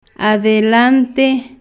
Phonological Representation ade'lante
adelante.mp3